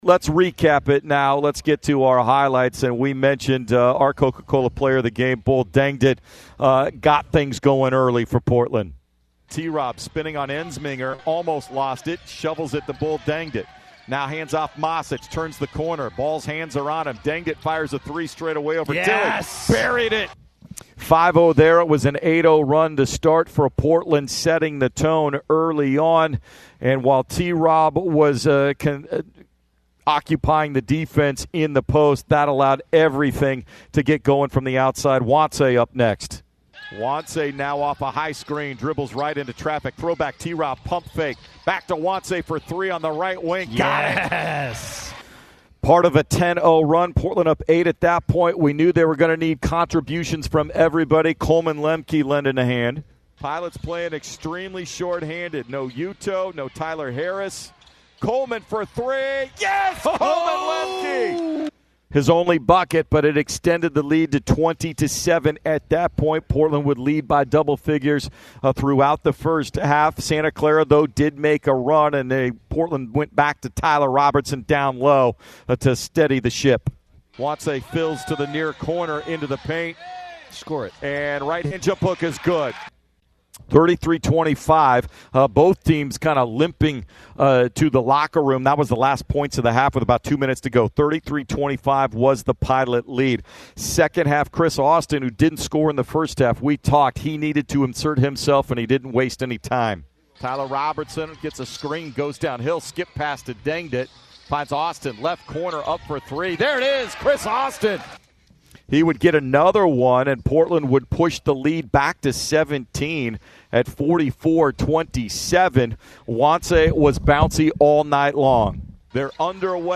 Radio Highlights vs. Santa Clara